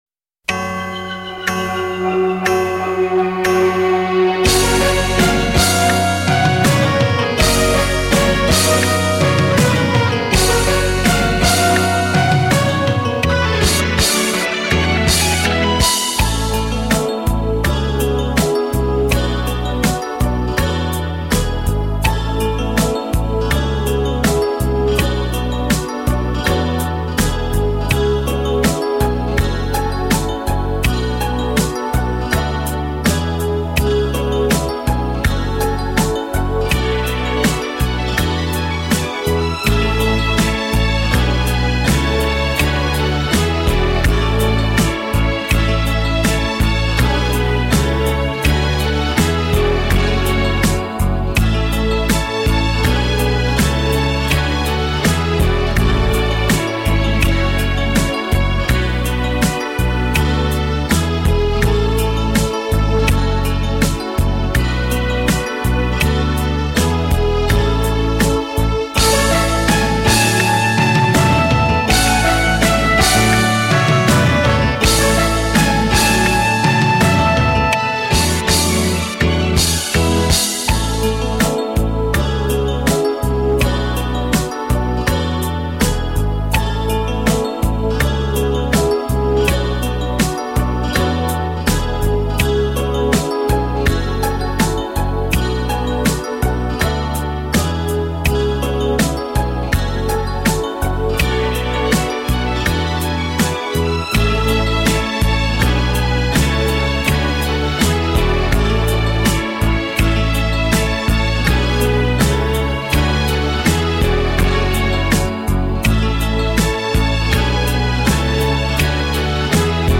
Особое место в символике занимает гимн школы — музыкальное произведение, которое звучит на важных мероприятиях и торжествах.
музыка Хайруллина Р.Р., слова народные